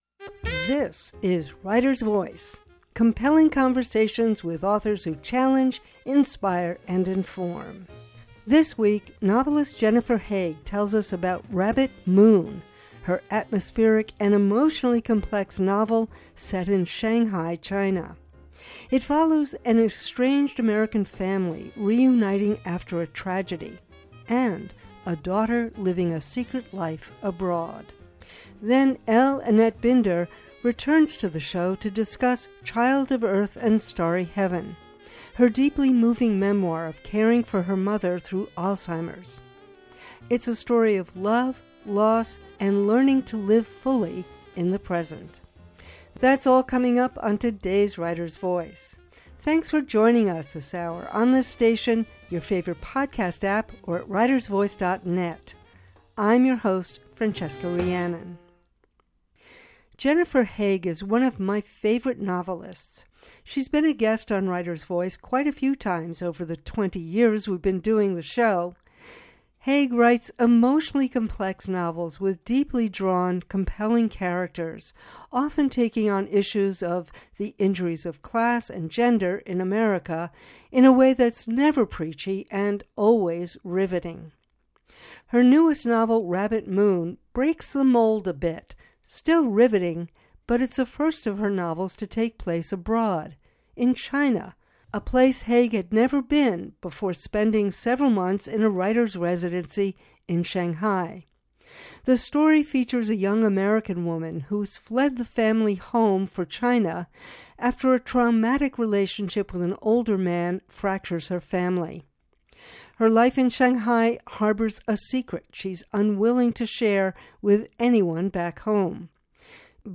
Writer’s Voice: compelling conversations with authors who challenge, inspire, and inform.